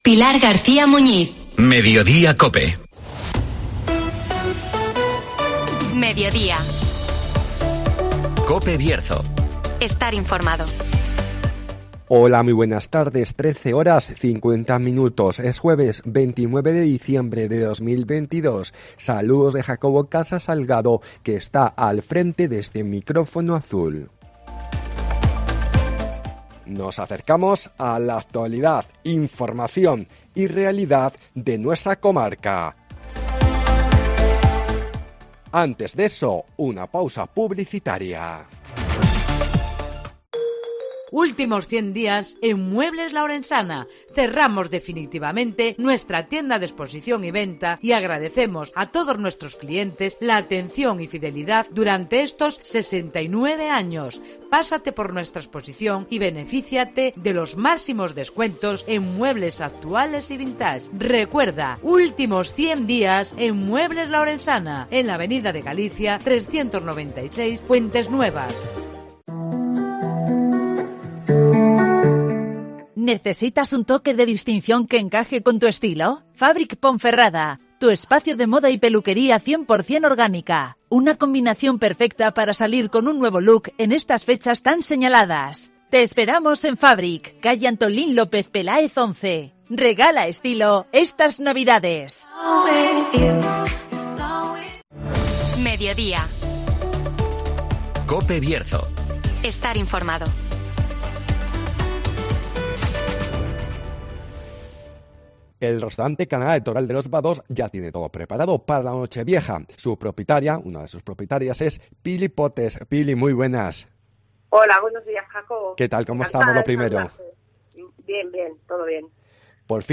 Nochevieja en el Restaurante Canada (Entrevista